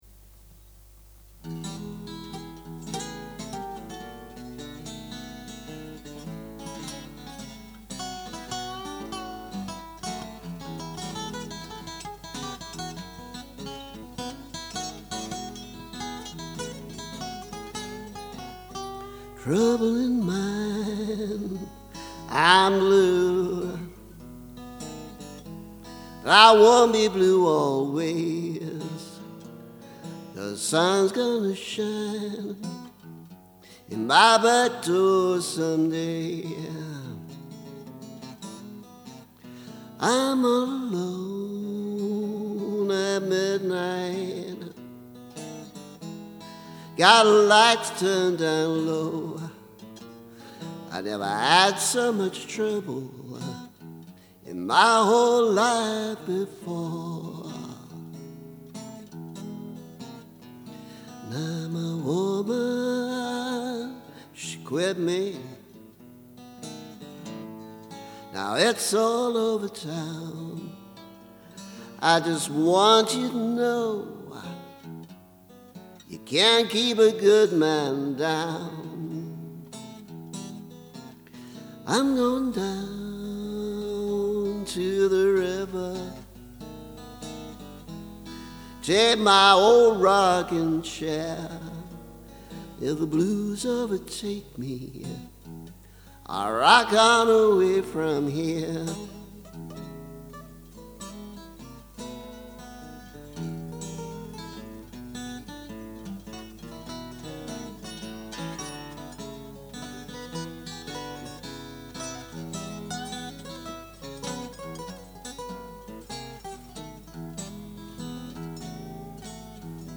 Classic 8-bar blues